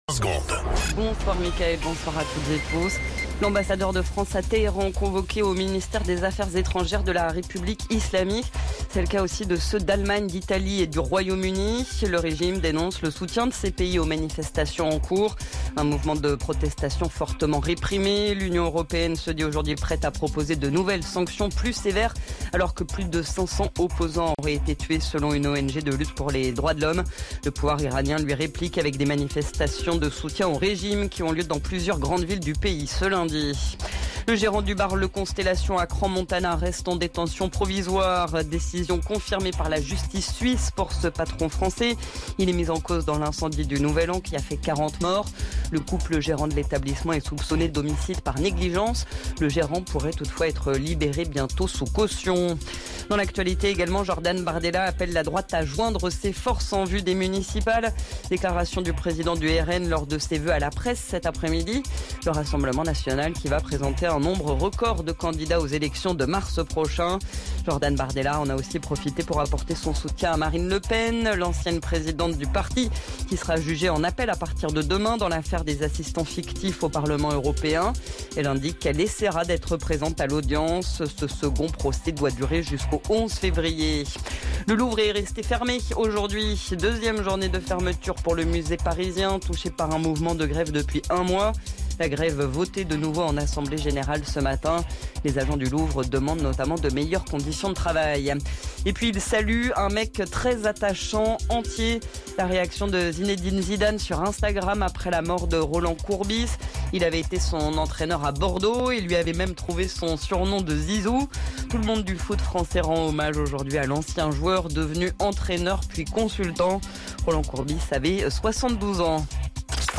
Flash Info National 12 Janvier 2026 Du 12/01/2026 à 17h10 .